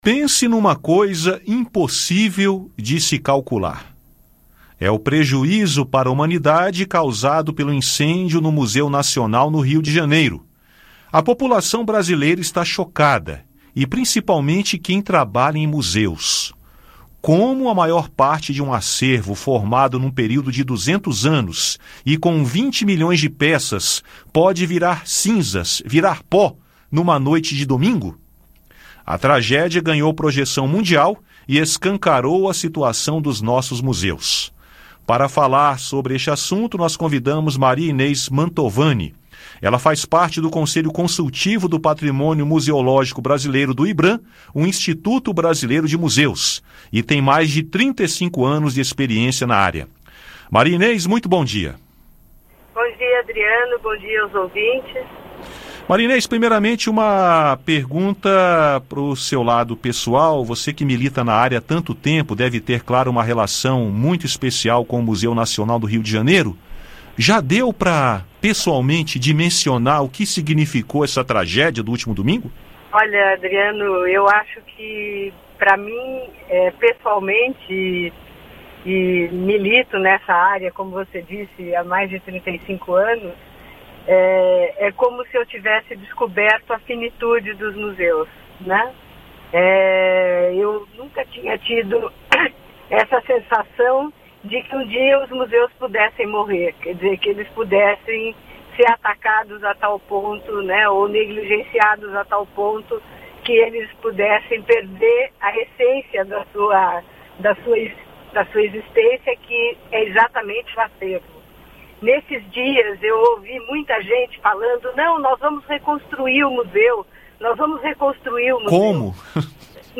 Ouça o áudio com a entrevista completa.